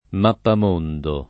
mappam1ndo] s. m. — pl. -di, da sempre, nonostante la derivaz. dalla locuz. femm. lat. mappa mundi [m#ppa m2ndi] «mappa del mondo»: Nominativi fritti e mappamondi [nominat&vi fr&tti e mmappam1ndi] (Burchiello)